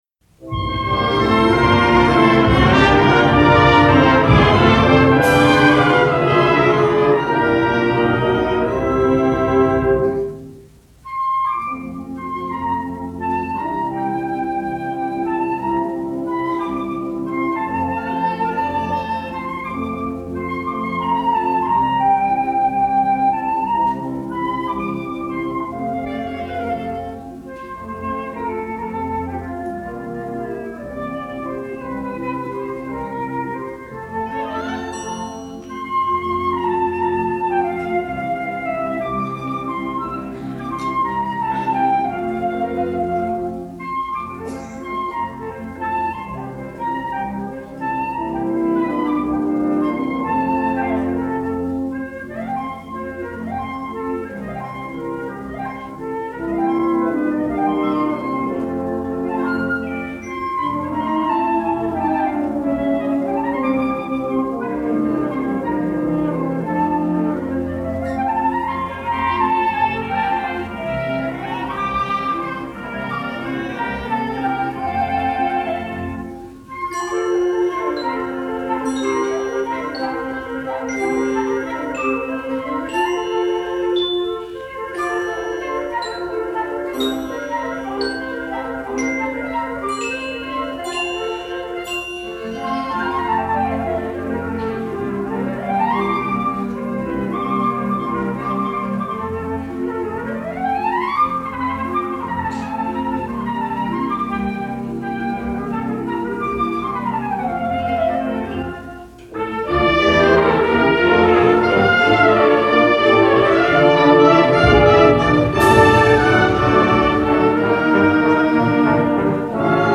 Voicing: Flute Solo w/ Band